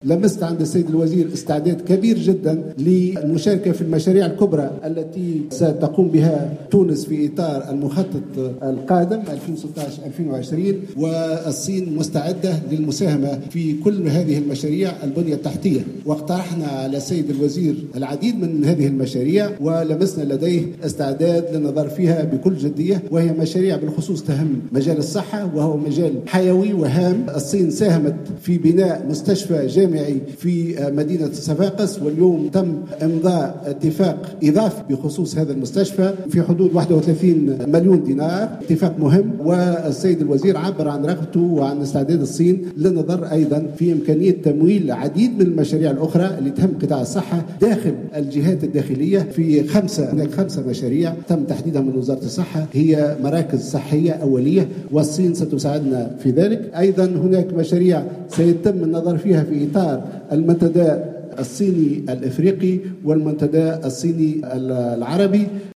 أعلن وزير الشؤون الخارجية خميس الجهيناوي في مؤتمر صحفي مشترك عقده اليوم الجمعة، مع نظيره الصيني يانغ يي الذي يؤدي زيارة عمل الى تونس لمدة يومين، أنه تم امضاء اتفاق اضافي بحوالي واحد وثلاثين مليون دينار بخصوص المستشفى الذي سيُنجز بمدينة صفاقس مشيرا الى أن الصين مستعدة للمساهمة في كل المشاريع الكبرى المبرمجة في المخطط التنموي القادم.